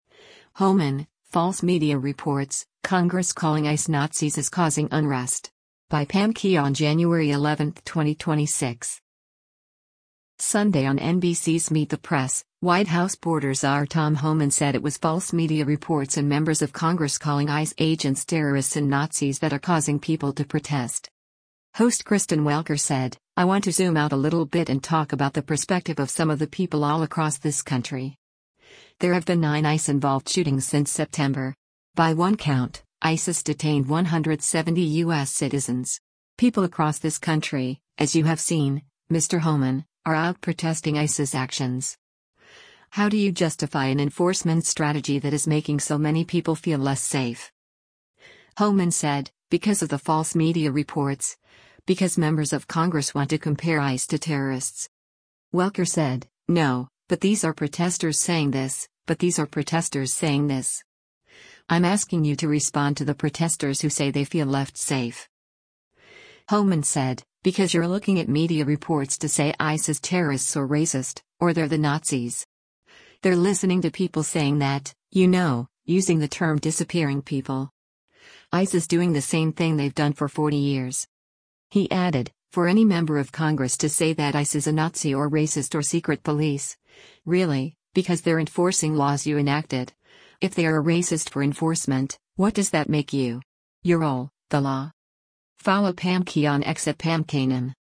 Sunday on NBC’s “Meet the Press,” White House border czar Tom Homan said it was false media reports and members of Congress calling ICE agents “terrorists” and “Nazis” that are causing people to protest.